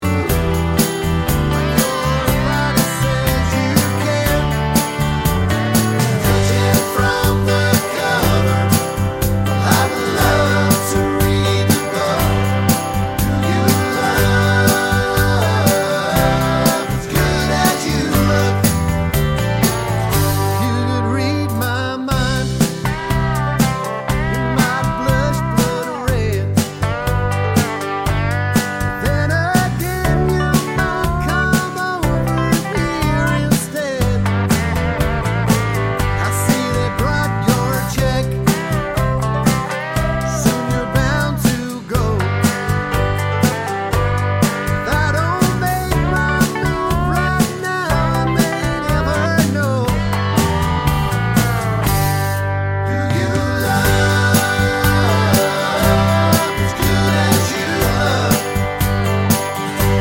With Harmony Country (Male) 2:58 Buy £1.50